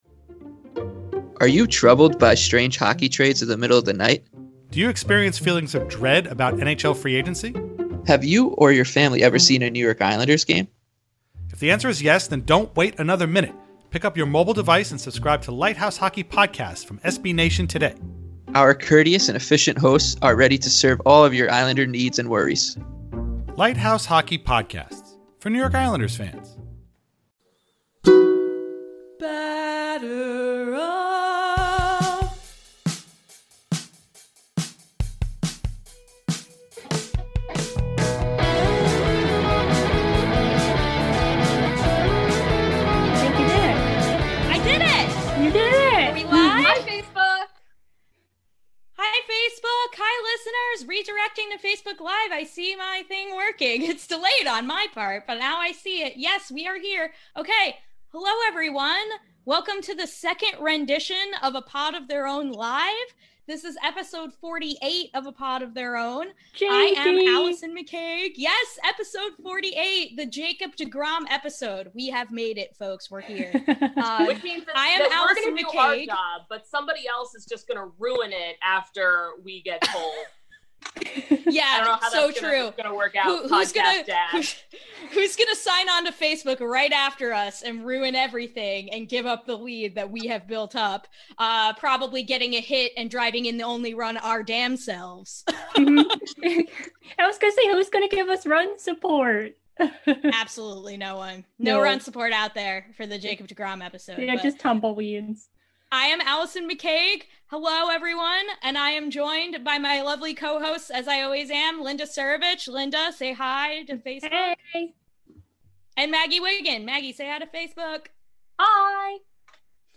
Welcome back to A Pod of Their Own, a show by the women of Amazin’ Avenue where we talk all things Mets, social justice issues in baseball, and normalize female voices in the sports podcasting space.
This week, we took to Facebook Live to show our listeners our favorite pieces of Mets memorabilia we own, sharing the memories behind each one—ticket stubs, baseballs, scorecards, and more! We also wrap things up like we do every week with Walk-off Wins, where each of us talks about what’s making us happy this week, baseball-related or otherwise.